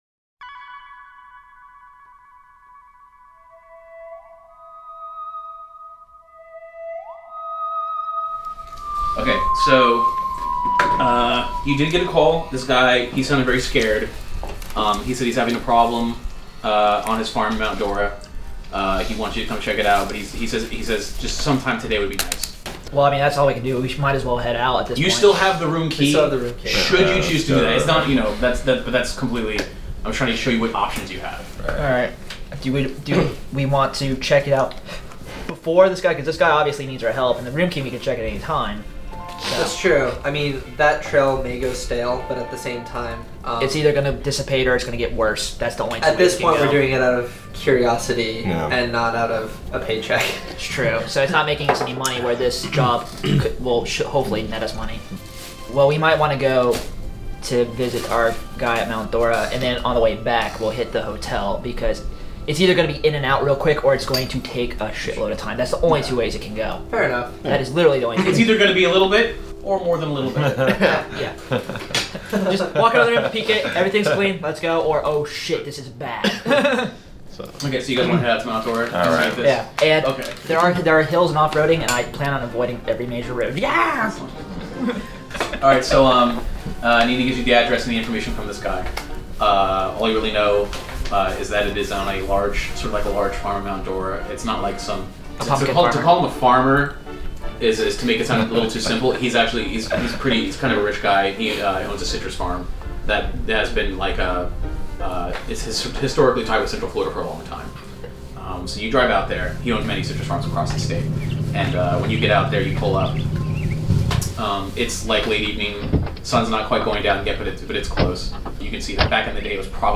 Ghostbusters: Resurrection is a cinematic roleplaying podcast set in the present day with the same lore, weirdness, and comedy of the films, games, and comics.